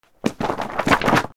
雑誌が落ちる音 07 Powerful&Short
/ J｜フォーリー(布ずれ・動作) / J-10 ｜転ぶ　落ちる